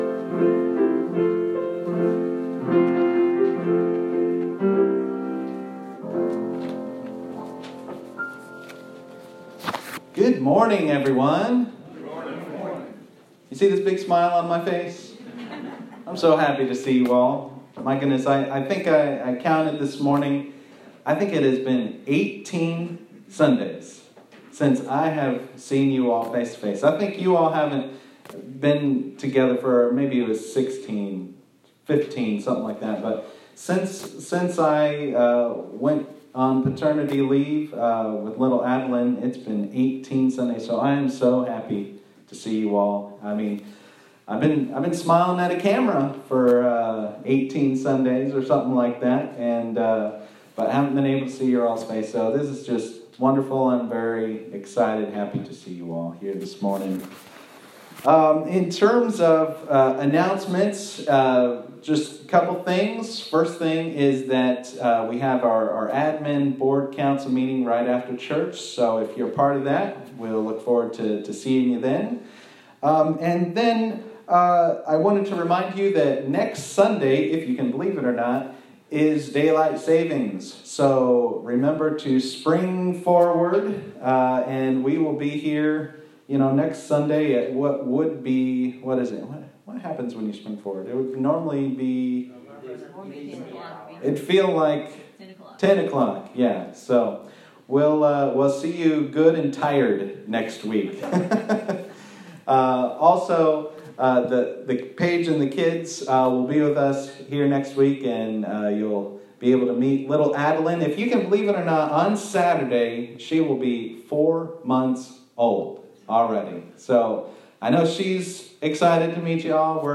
This is Part 3 of a 7-Part sermon series titled “Easter according to Paul” preached at Mt. Gilead UMC in Georgetown, KY.